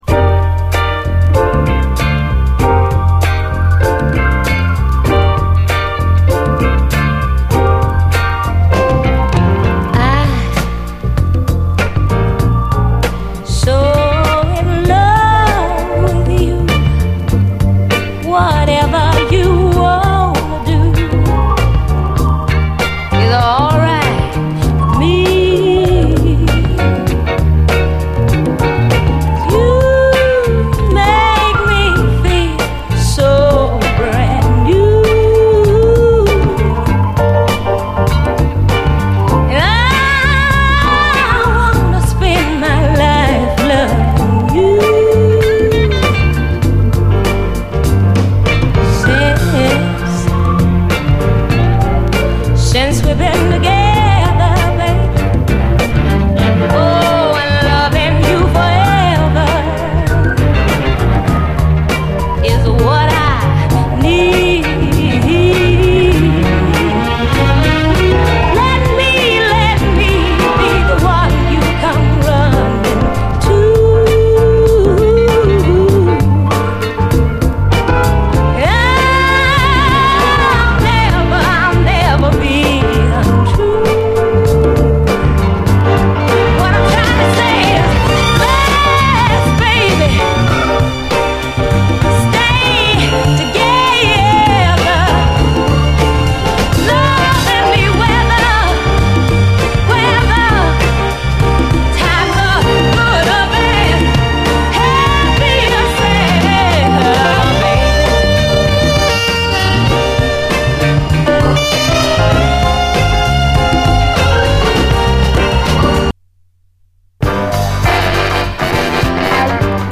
SOUL, 70's～ SOUL